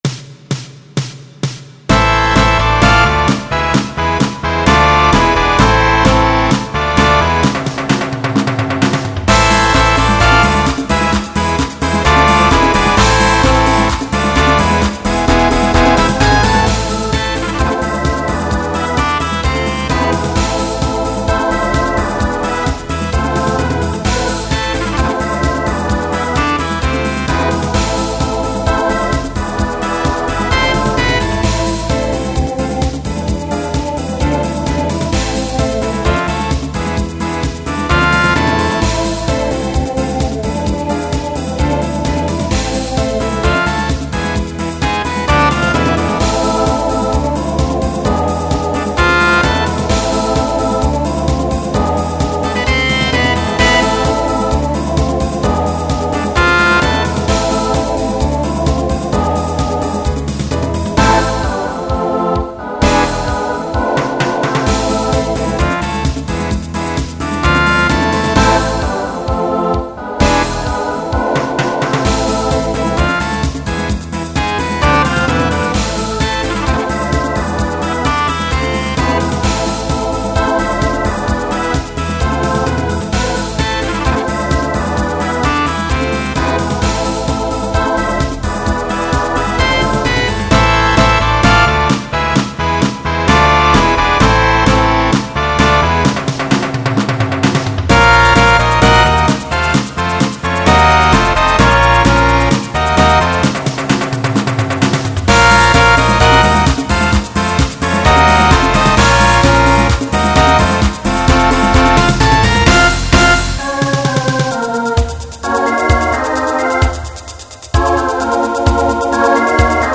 Genre Latin , Pop/Dance Instrumental combo
Opener Voicing SATB